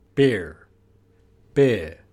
beer_am_br.mp3